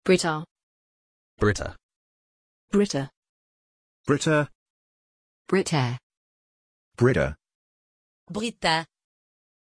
Aussprache von Britta
pronunciation-britta-en.mp3